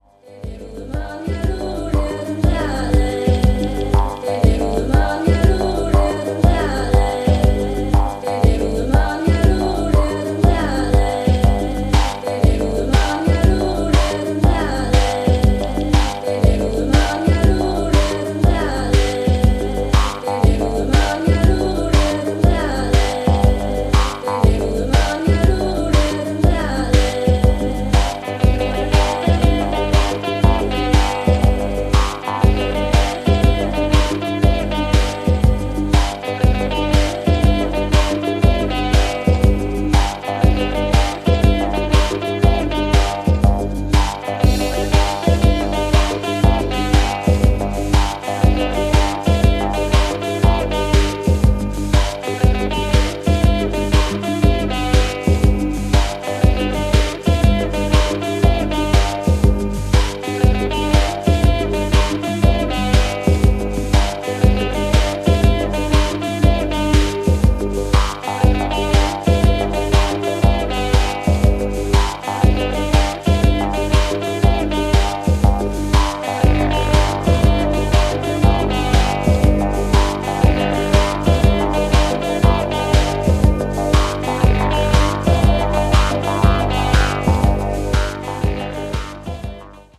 Disco House